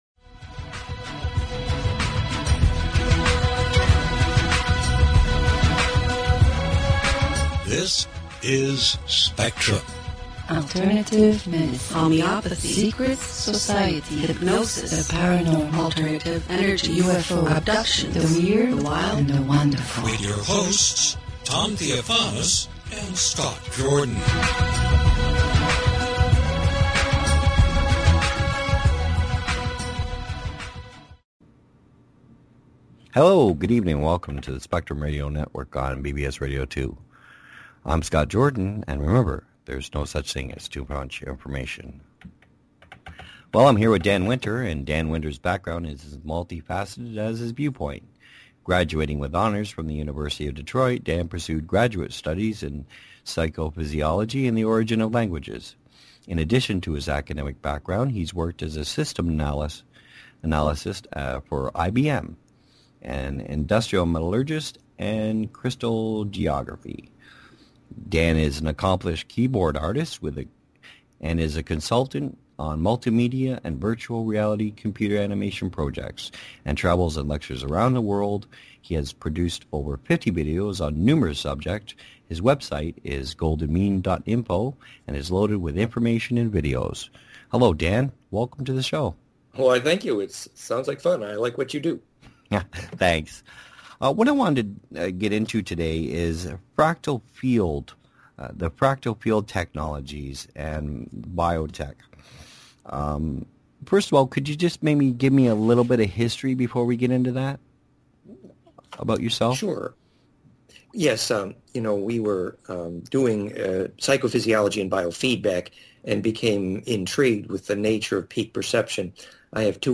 Talk Show Episode, Audio Podcast, Spectrum_Radio_Network and Courtesy of BBS Radio on , show guests , about , categorized as